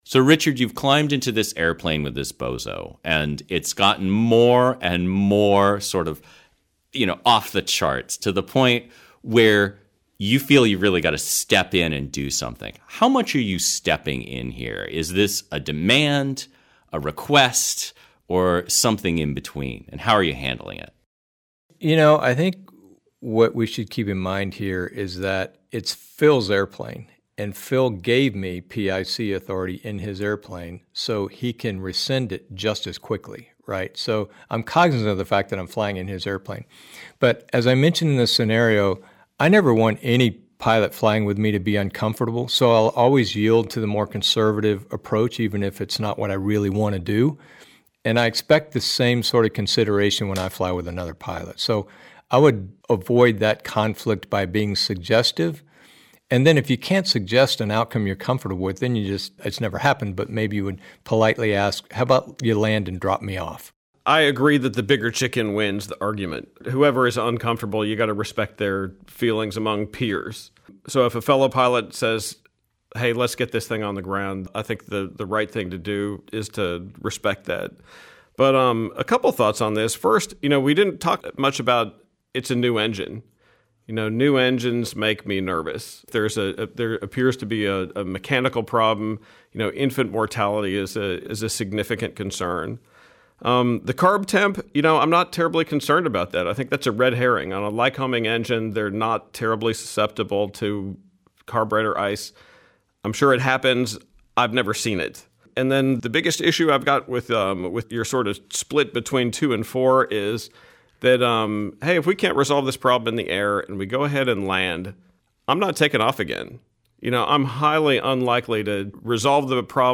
Rough_Running_at_Marathon_roundtable.mp3